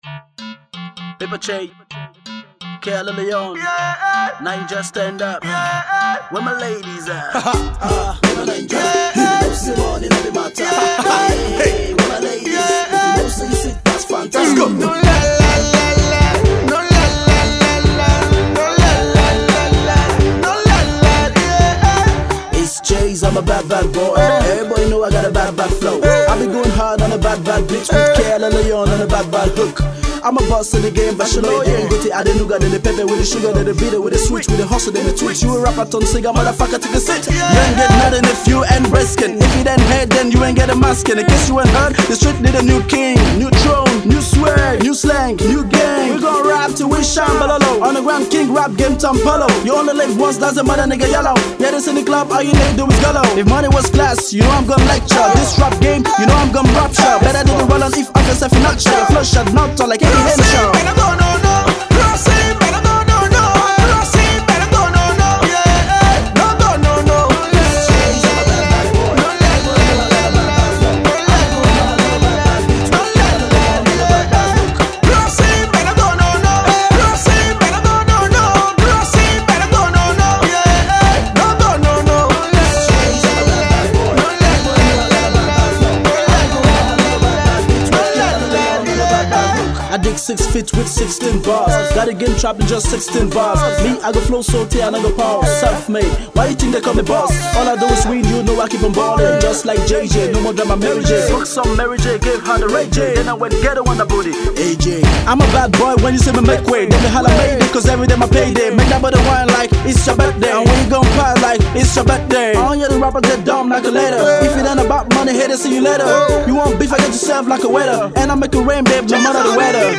beat heavy party track